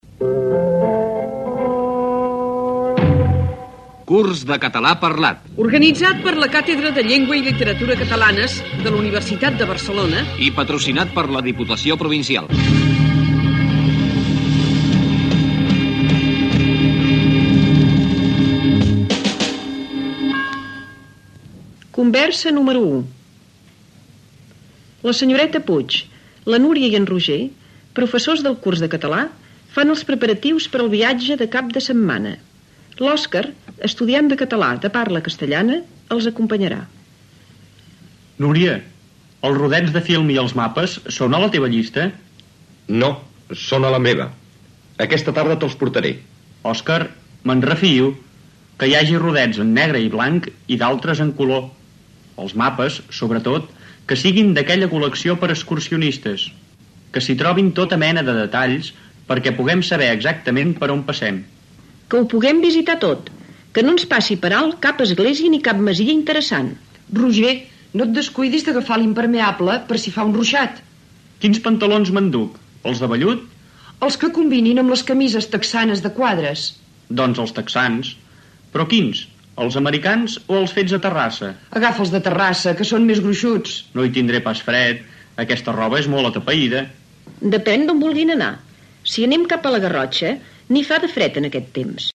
Inici de la lliçó de català.